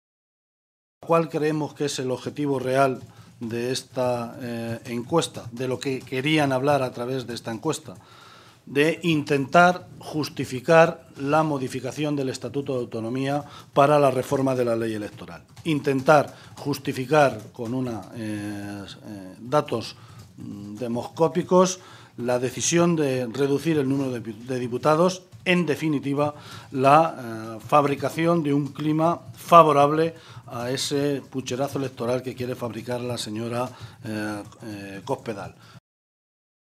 José Luís Martínez Guijarro, portavoz del Grupo Parlamentario Socialista
Cortes de audio de la rueda de prensa